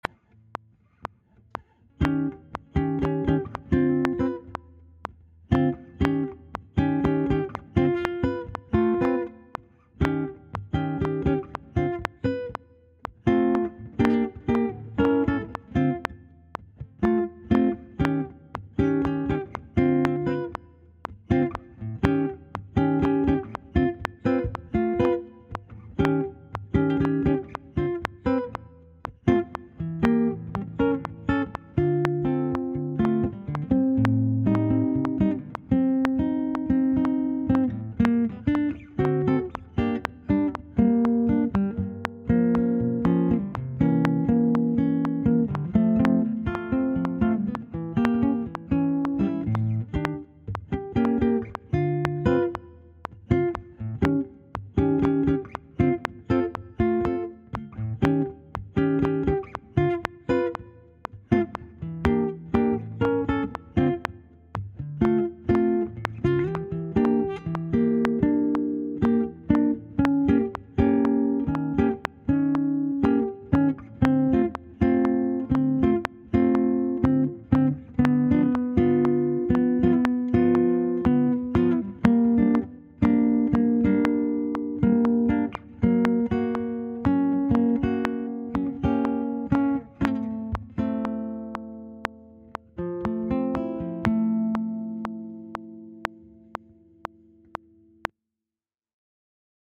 Dm120